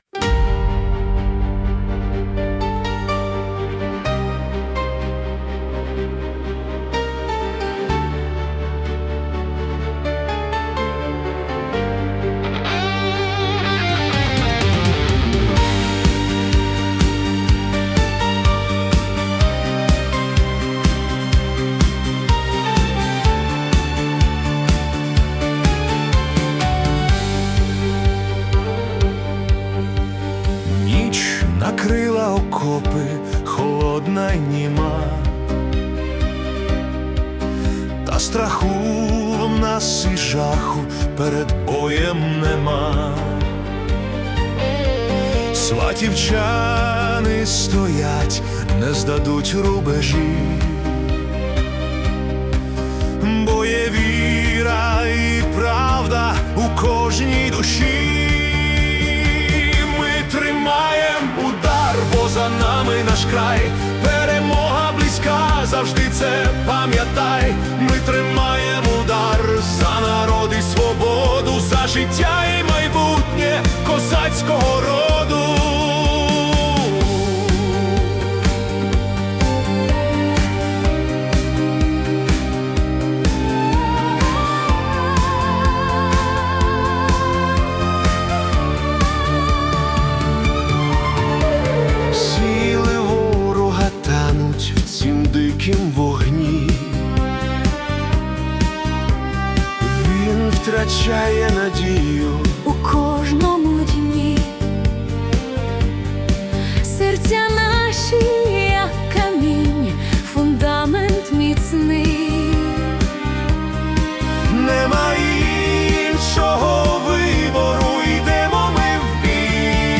🎵 Жанр: Heroic Disco-Pop